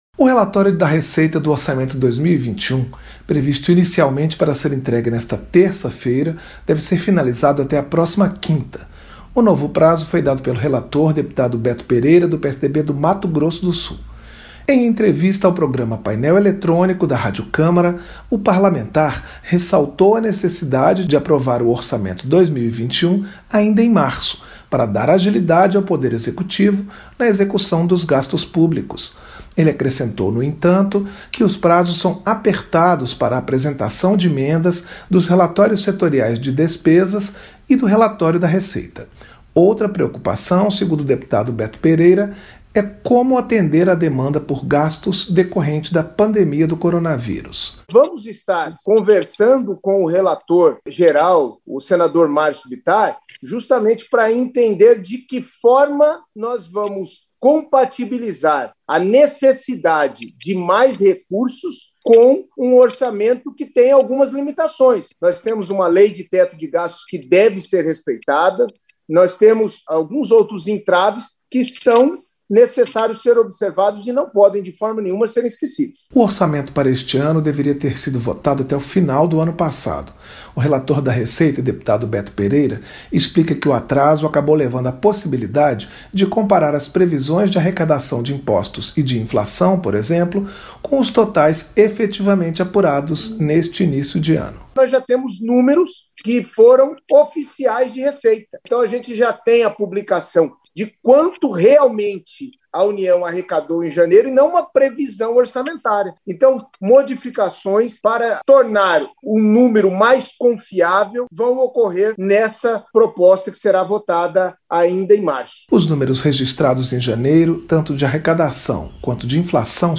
Em entrevista ao programa Painel Eletrônico, da Rádio Câmara, o parlamentar ressaltou a necessidade de aprovar o Orçamento de 2021 ainda em março, para dar agilidade ao Poder Executivo na execução dos gastos públicos.